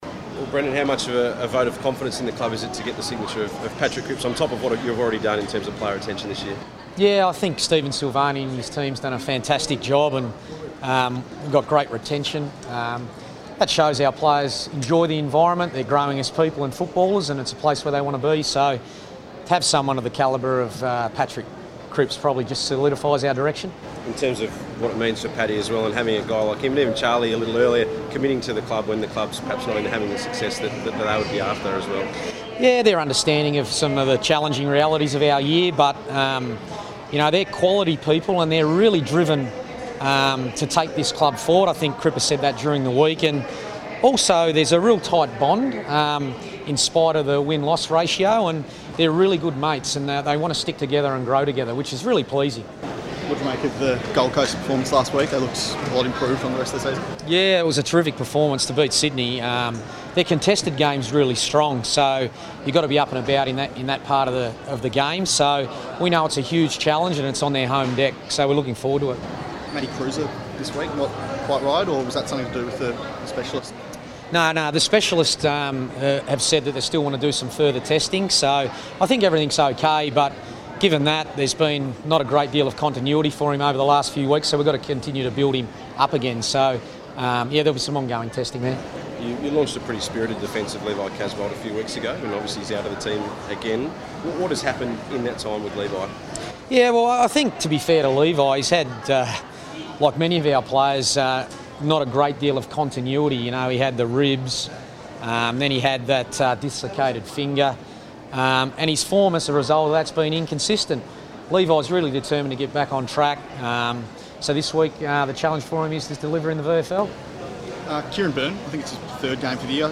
Brendon Bolton press conference | July 27
Carlton coach Brendon Bolton speaks to the media at Melbourne Airport ahead of the Blues' trip to Queensland to face the Gold Coast Suns.